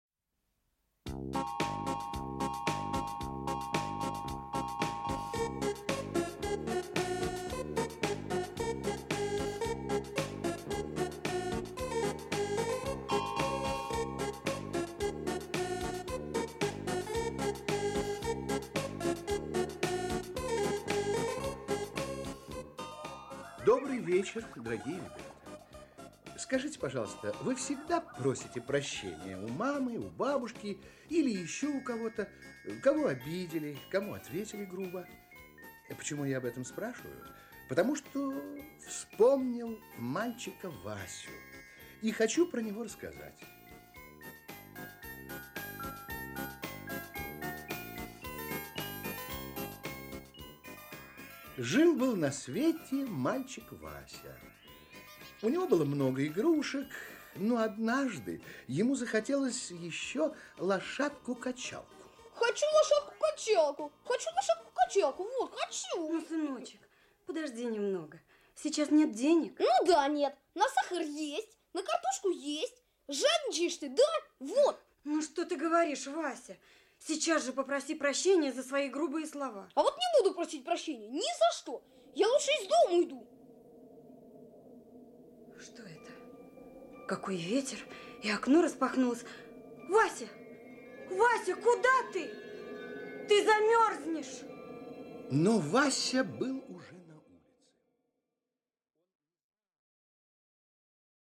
Аудиокнига Не буду просить прощенья | Библиотека аудиокниг
Aудиокнига Не буду просить прощенья Автор Евстолия Прокофьева Читает аудиокнигу Актерский коллектив.